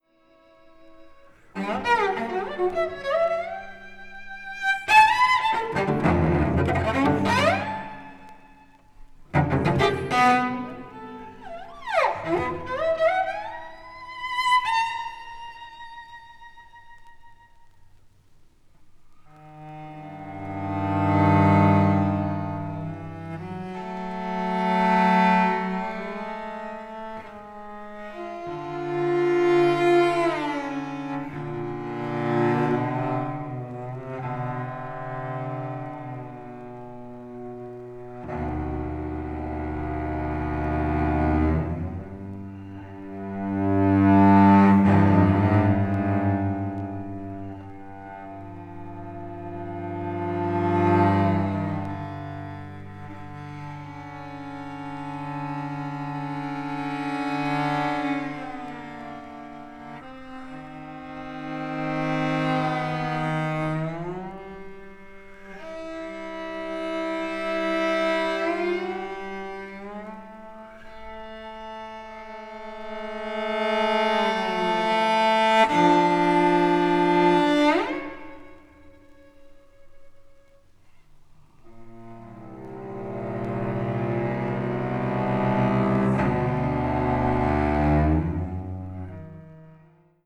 The recording quality is also outstanding.